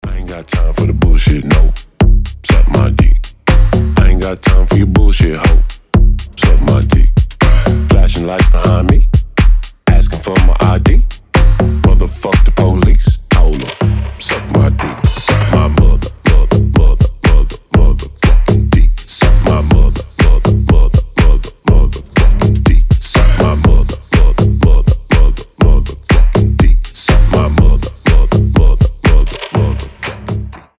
ритмичные
Bass House
качающие
G-House
Крутой G-house